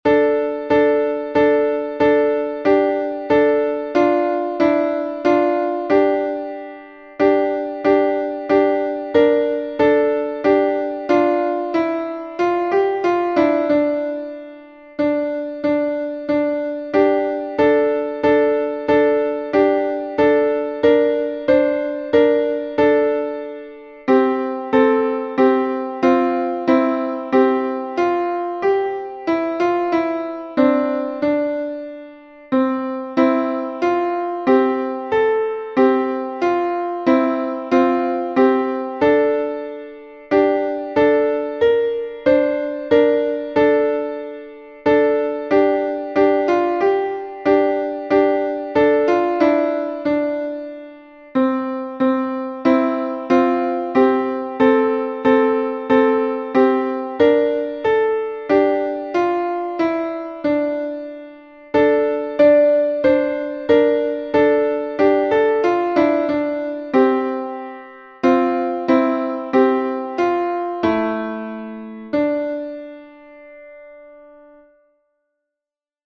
Византийский напев XV века